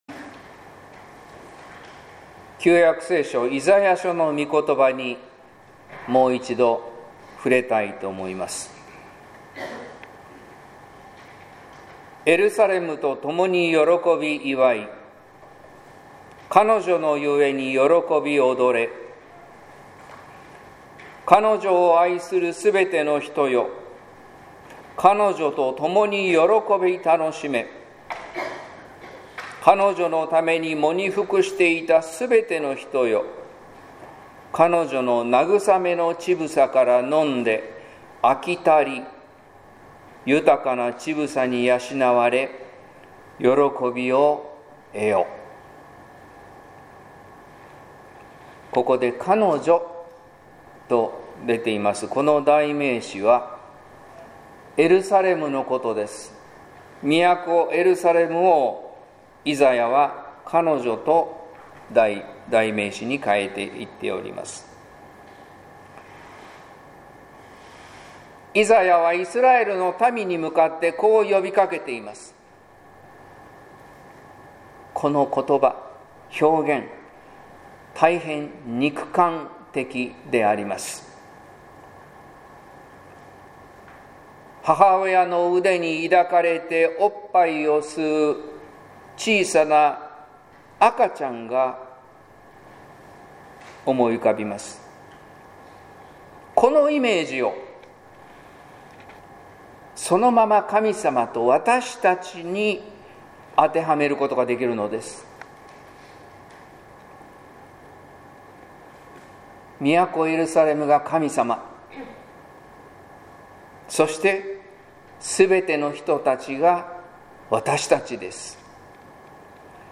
説教「肉付けしたメッセージ」（音声版）